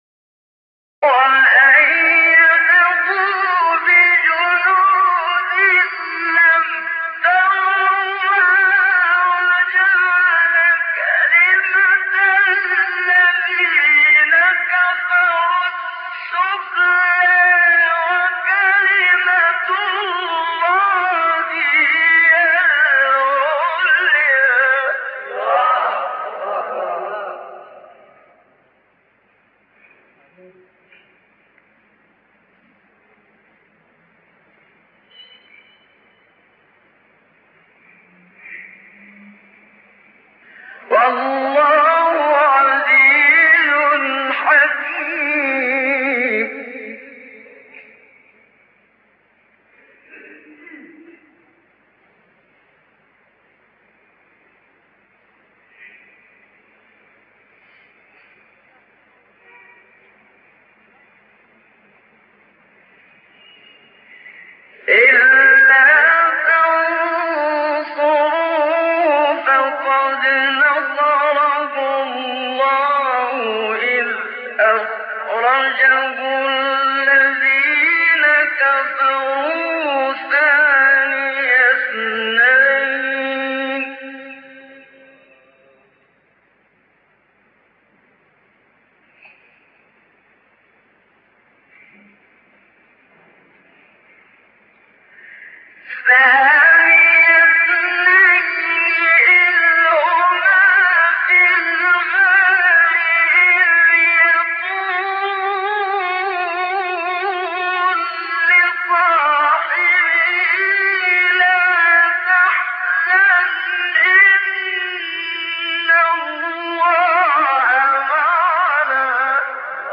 سوره : توبه آیه: 40 استاد : محمد صدیق منشاوی مقام : سه گاه قبلی بعدی